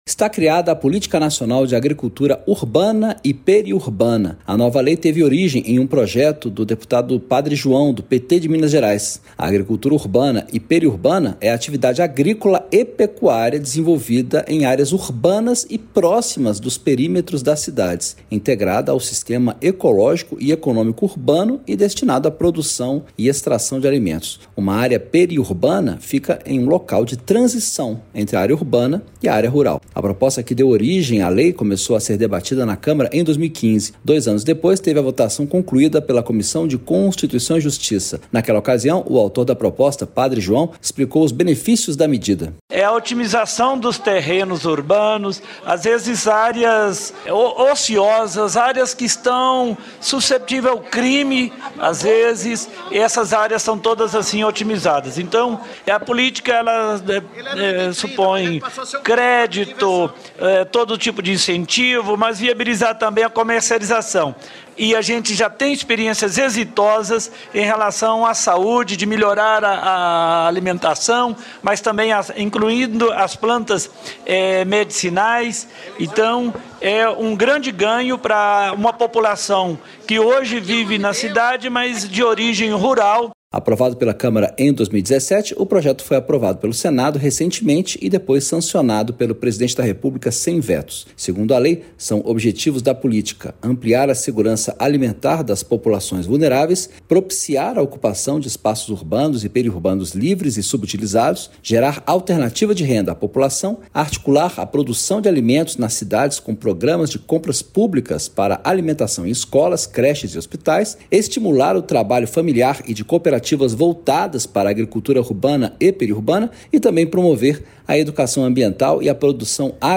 Lei cria política nacional para agricultura em áreas urbanas e de transição entre cidades e áreas rurais - Radioagência